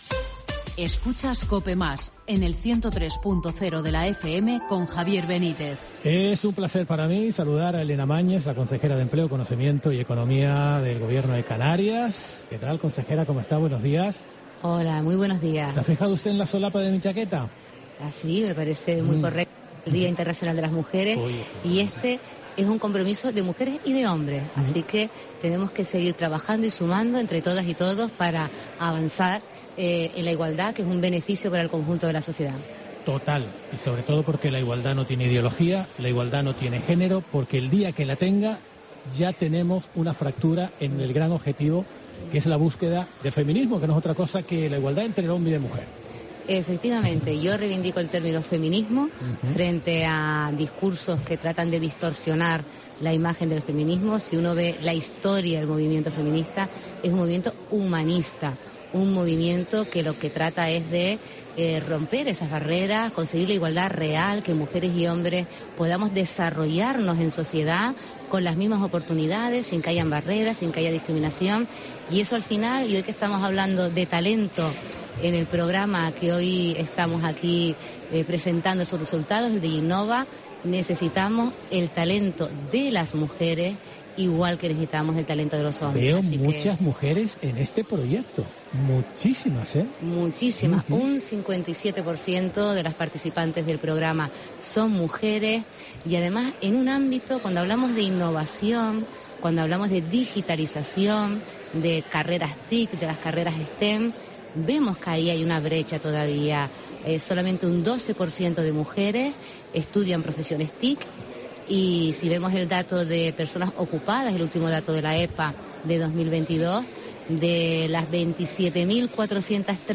La Mañana de COPE Gran Canaria se desplazó hasta el Gabinete Literario, lugar elegido para la clausura del programa Diginnova.